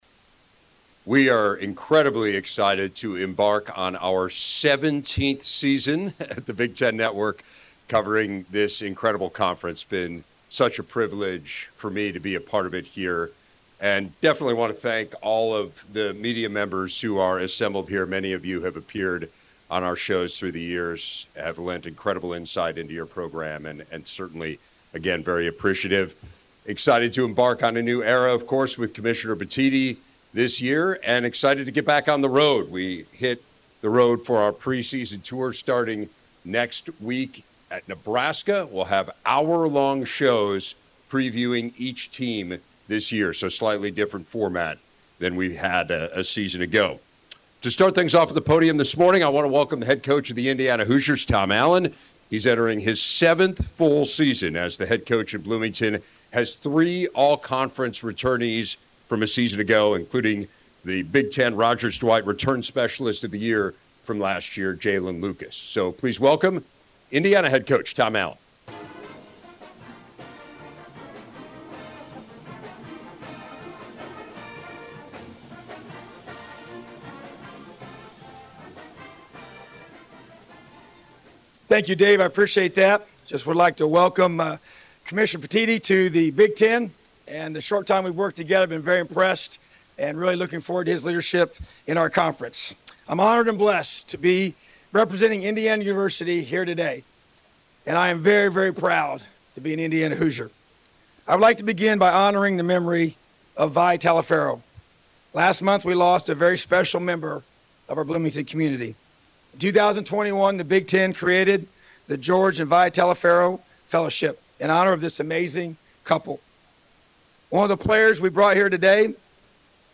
Indiana Hoosiers Team Outlook: Head coach Tom Allen gives media a preview at 2023 Big Ten Football Media Days